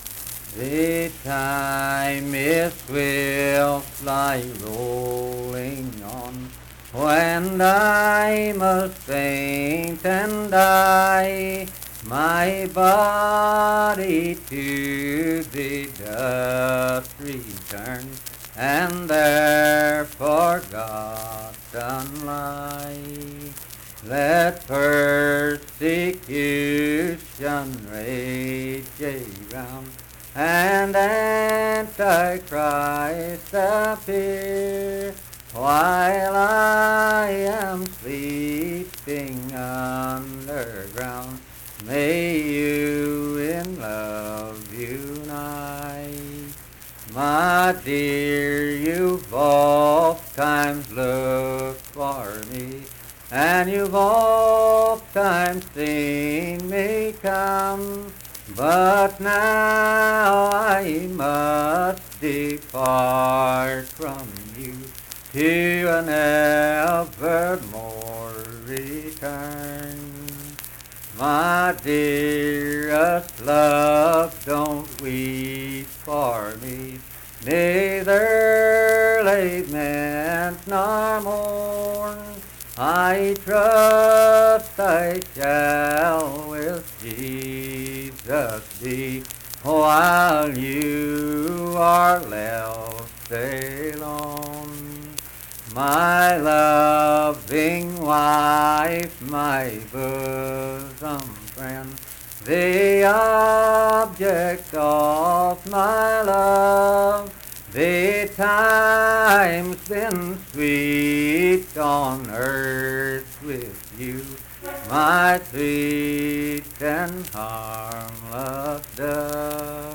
Unaccompanied vocal music performance
Performed in Kliny, Pendleton County, WV.
Voice (sung)